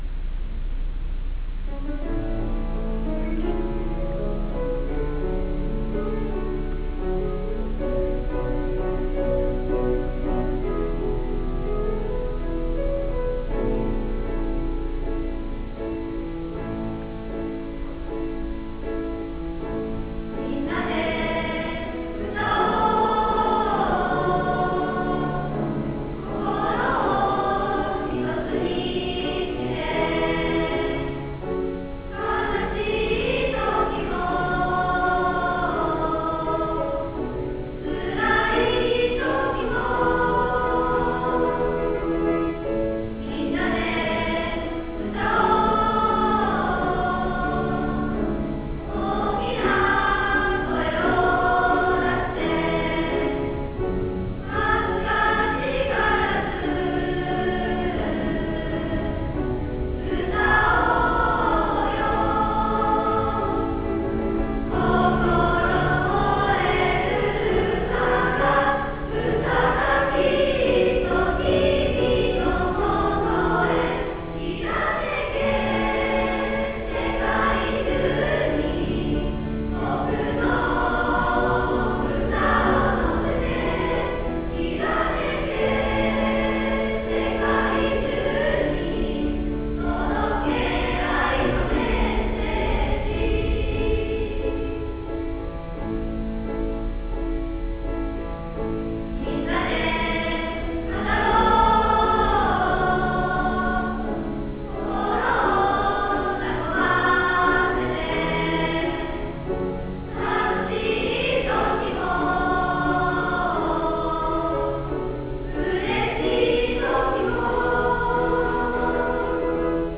下の曲名をクリックすると、当日のハーモニーを聞くことができます。